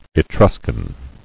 [E·trus·can]